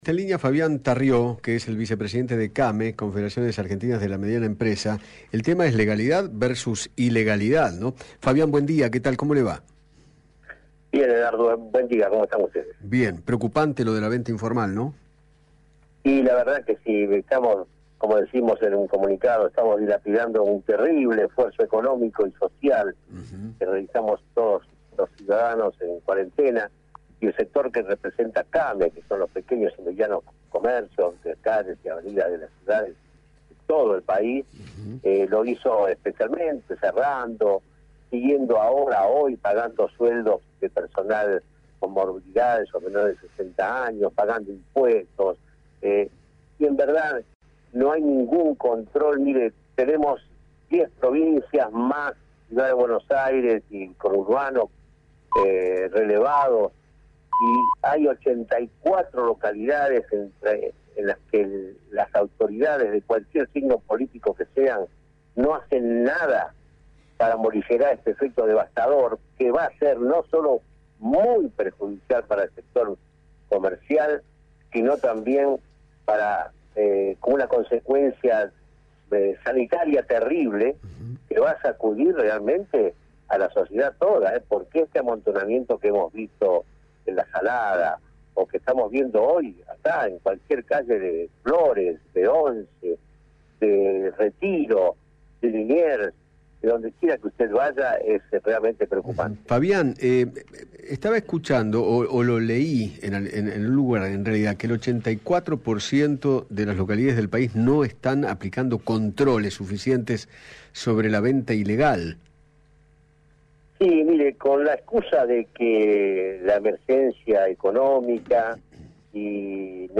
dialogó con Eduardo Feinmann acerca del incremento de la venta informal de productos y servicios